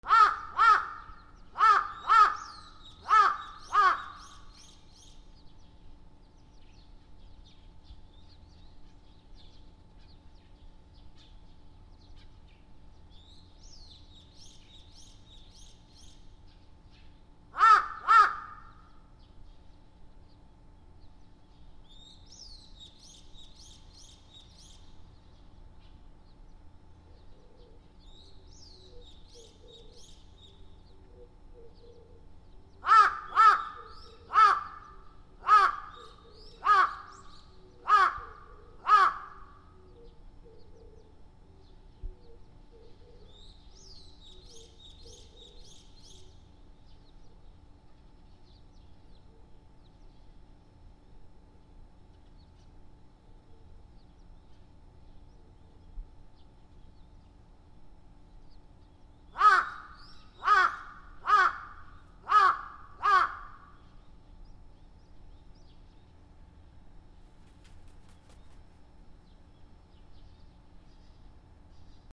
カラス
/ D｜動物 / D-05 ｜鳥 / 20｜カラス